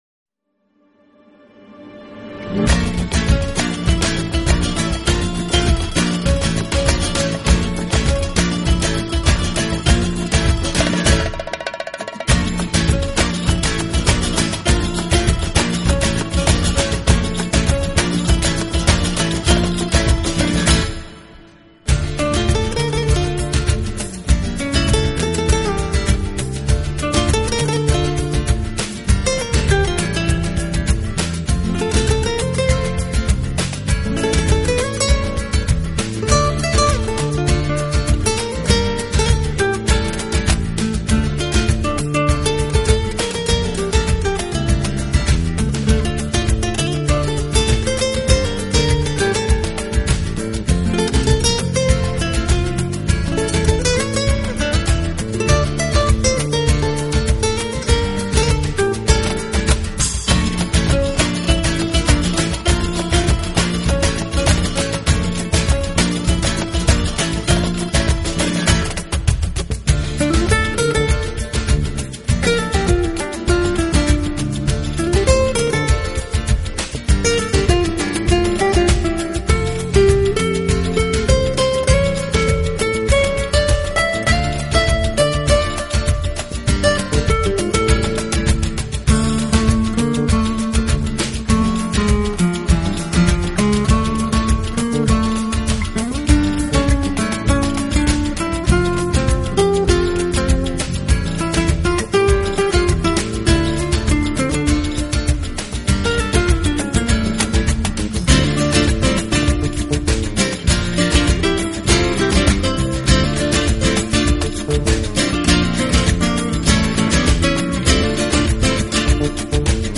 【新世纪纯音乐】
弱一点，但更显轻快平和，象天边的那一抹微云，悠远而自在。